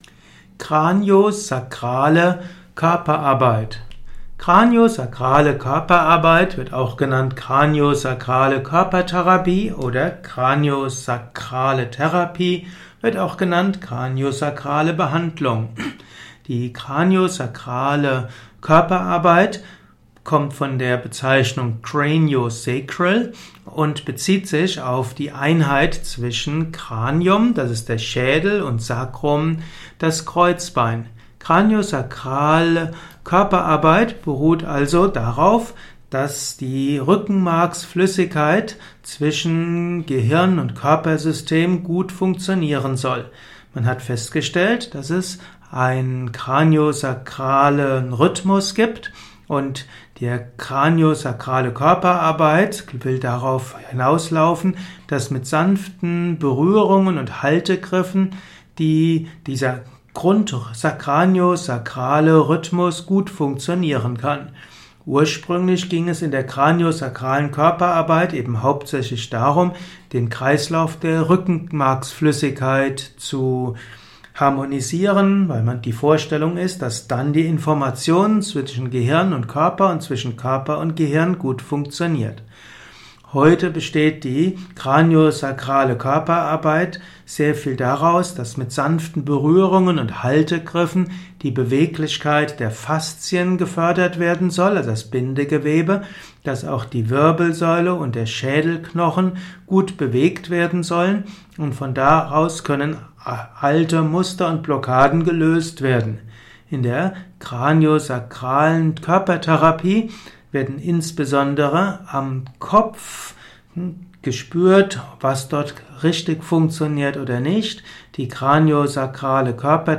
Craniosacrale Körperarbeit in diesem kurzen Vortrags-Podcast.